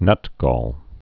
(nŭtgôl)